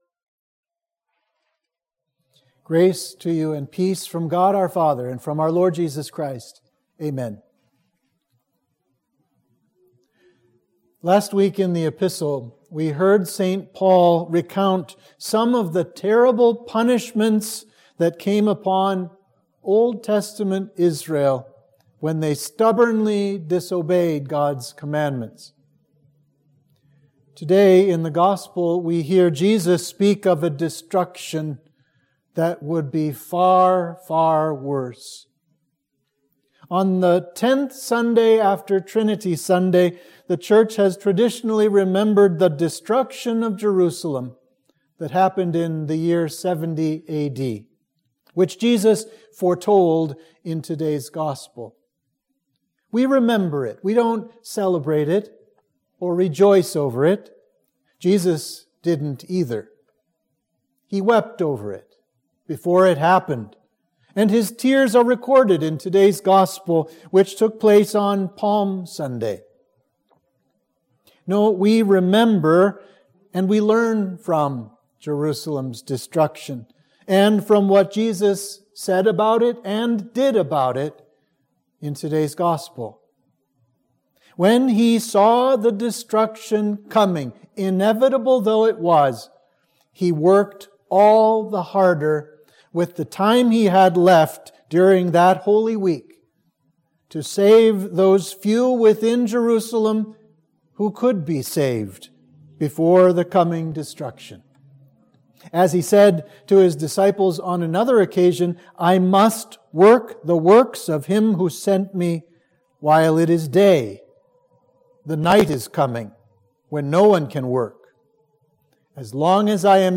Sermon for Trinity 10